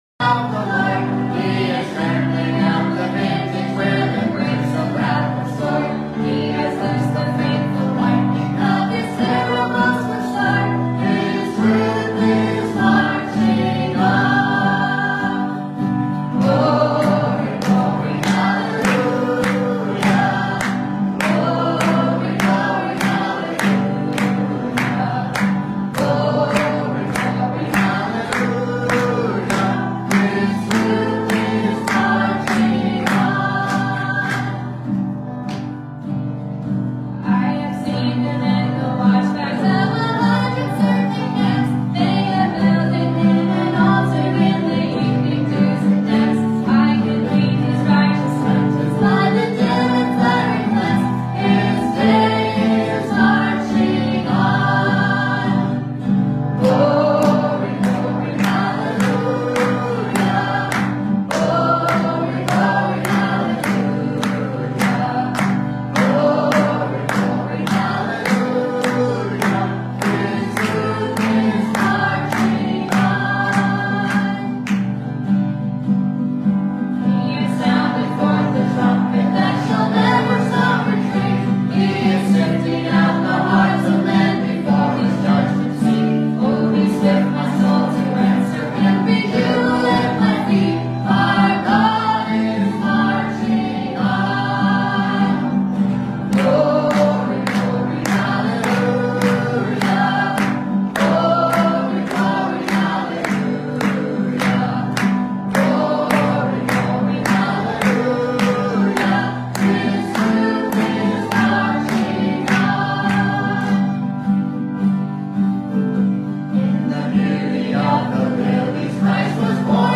Passage: Romans 8:1 Service Type: Sunday Morning